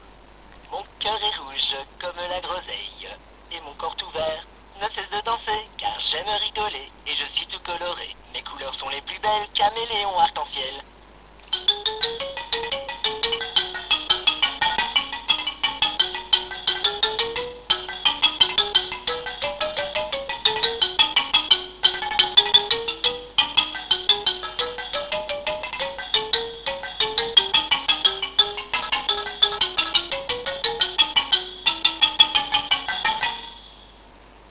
Voici Léon le Caméléon que le Pôpa Nowel m'a apporté aussi : il chante, joue de la musique des Caraïbes et s'allume
jouetcameleon.wav